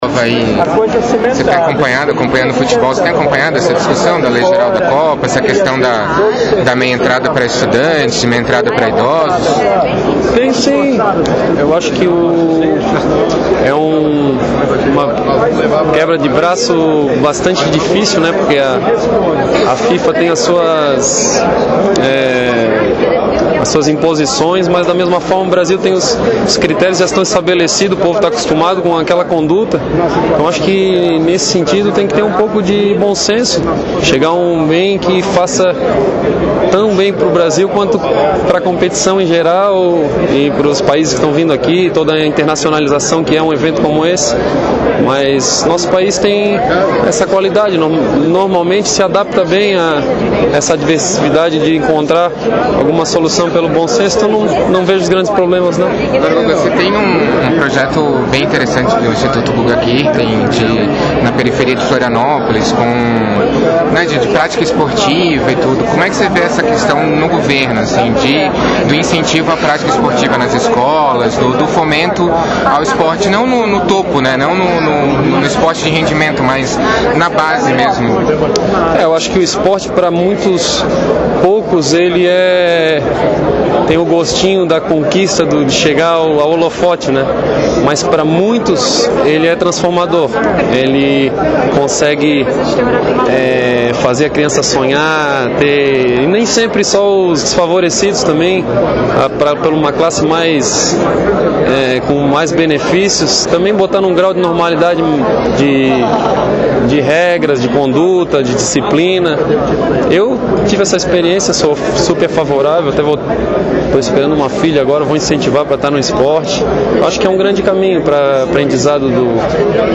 Em entrevista ao Congresso em Foco e à Rádio Câmara, Guga Kuerten disse que o país enfrenta uma "queda de braço" com a Fifa.
No meio do tumulto entre atender fãs e seguir para a premiação, Guga, como ficou conhecido nacionalmente, teve tempo para uma conversa rápida com o Congresso em Foco e com a Rádio Câmara ( ouça a íntegra da entrevista ).